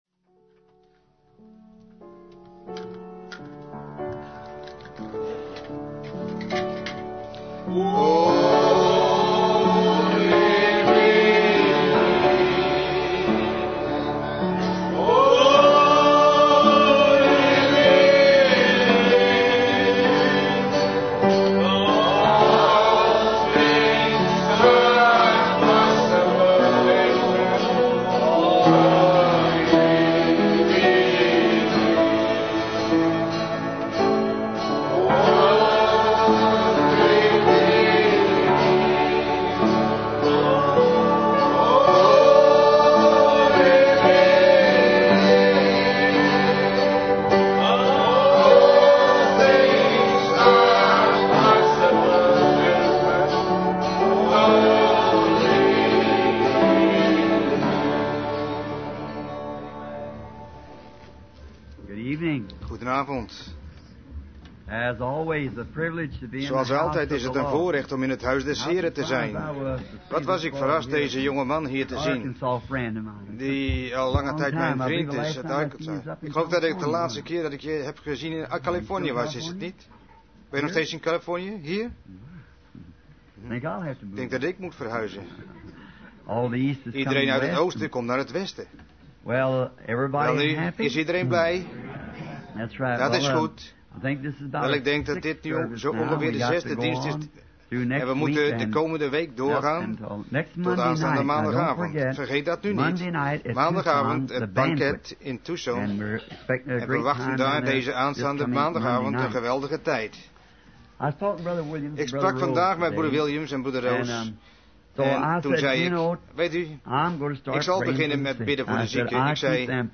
Vertaalde prediking "Awakening Jesus" door William Marrion Branham te Full Gospel church, Tempe, Arizona, USA, 's avonds op donderdag 17 januari 1963